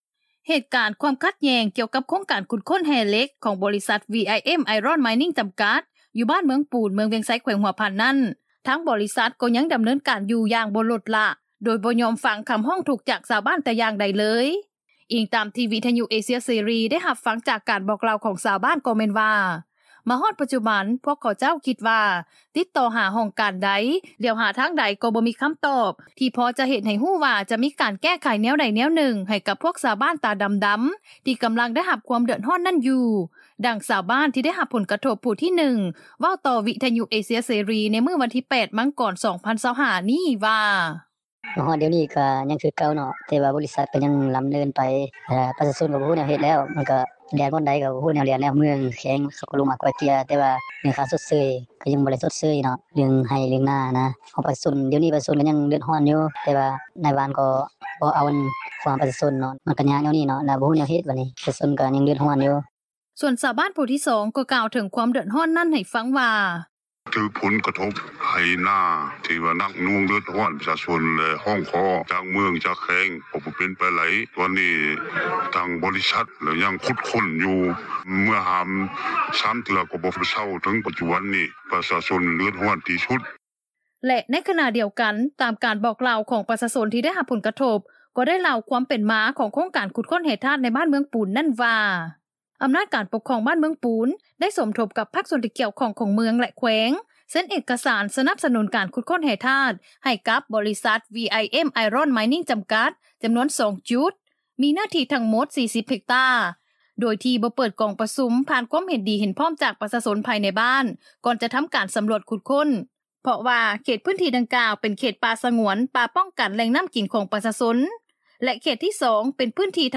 ດັ່ງຊາວບ້ານ ທີ່ໄດ້ຮັບຜົນກະທົບ ຜູ້ທີໜຶ່ງ ເວົ້າຕໍ່ວິທຍຸເອເຊັຽເສຣີ ໃນມື້ວັນທີ 8 ມັງກອນ 2025 ນີ້ວ່າ:
ສ່ວນຊາວບ້ານ ຜູ້ທີ 2 ກໍກ່າວເຖິງ ຄວາມເດືອດຮ້ອນນັ້ນ ໃຫ້ຟັງວ່າ: